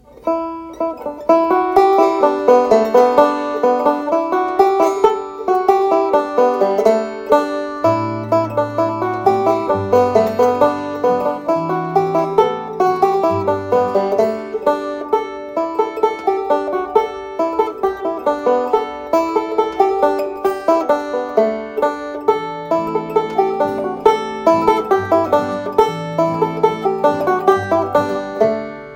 An archive of fiddle and dance tunes, both traditional and new, from Scandinavia, England, Ireland, Scotland, Cape Breton, New Brunswick, Quebec, New England, Appalachia and more, for traditional musicians.
Key : Ador Form : Reel
Region : Shetland